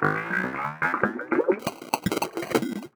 FX [ Space Transistor ].wav